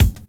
Wu-RZA-Kick 29.WAV